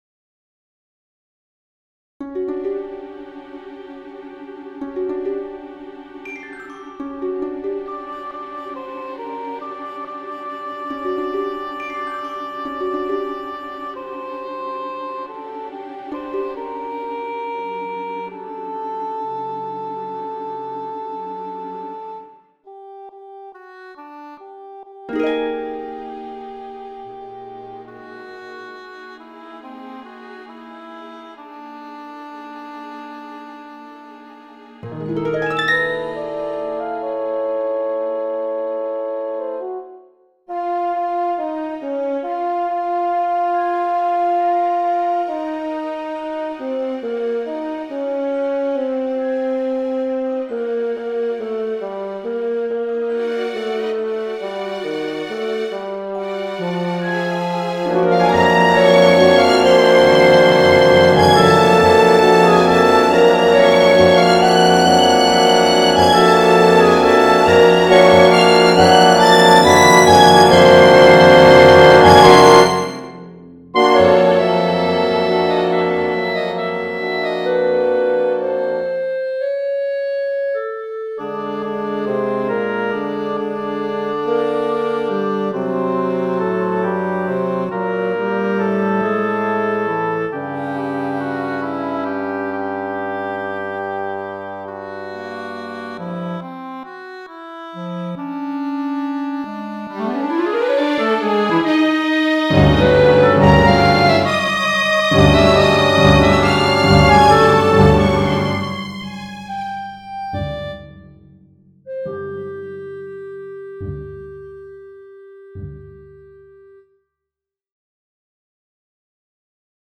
I extended the loud, lyrical section in the middle because I thought that the orchestration built up too much for its original short length
1. The celesta rolls at the beginning (I added -- there are two of them)
They are doubled by the strings and a clarinet trill
3. An annoying, singular harp chord (stacked fourth) around 30 seconds in, but maybe the software is playing it back too loud.
4. For the 2nd loud section near the end, the strings come in with a fast scale before the lyrical melody. It sounds sparse.